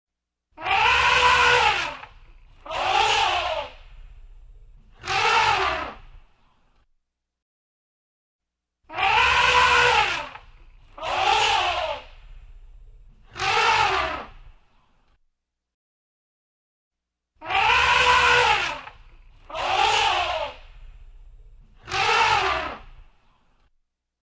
دانلود آهنگ فیل 2 از افکت صوتی انسان و موجودات زنده
دانلود صدای فیل 2 از ساعد نیوز با لینک مستقیم و کیفیت بالا
جلوه های صوتی